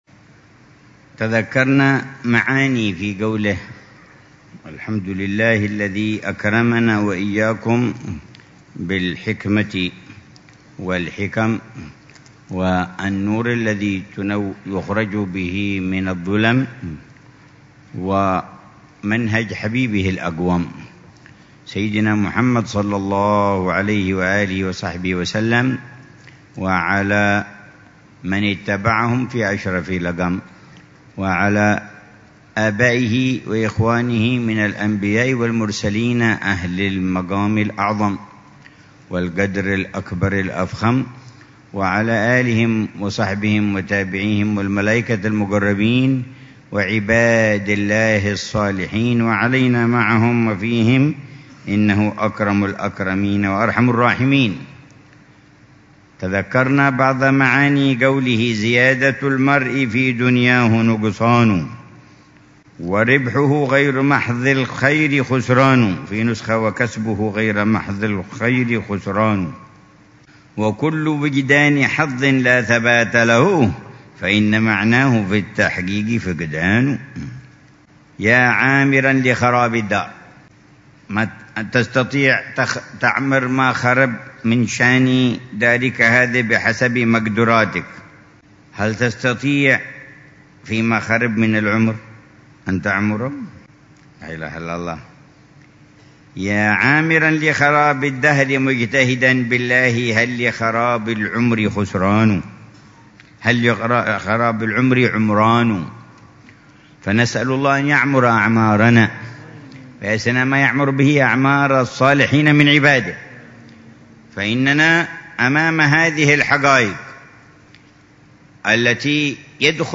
الدرس الثاني من شرح العلامة الحبيب عمر بن محمد بن حفيظ لقصيدة الإمام أبي الفتح البستي - عنوان الحِكم ، التي مطلعها ( زيادة المرء في دنياه نقص